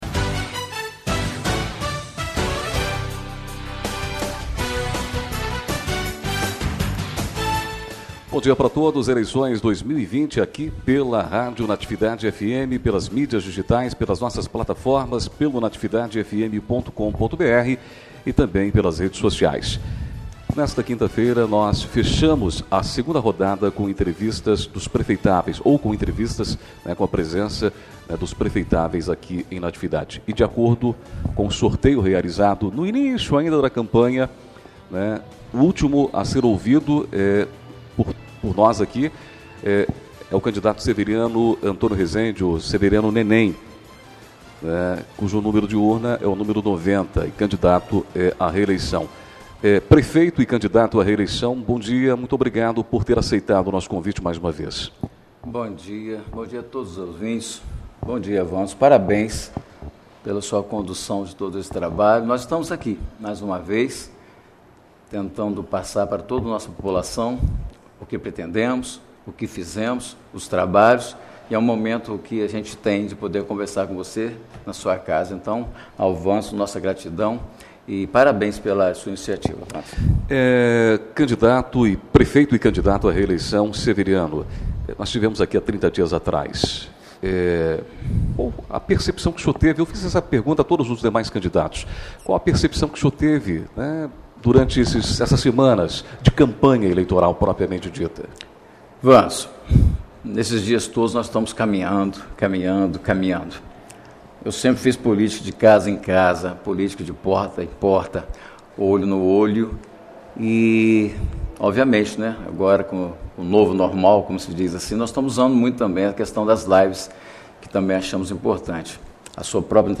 2ª Rodada de entrevistas
12 novembro, 2020 ENTREVISTAS, NATIVIDADE AGORA